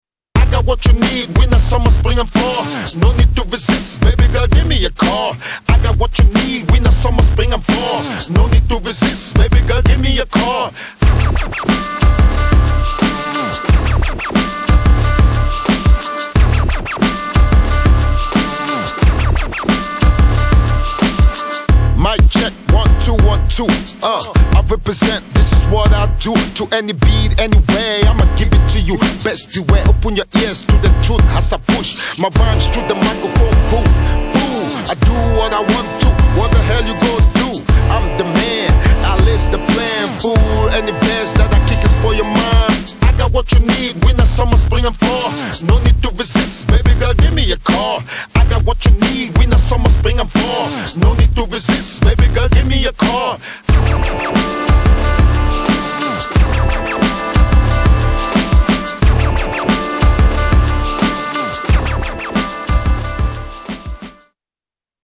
Дабстеп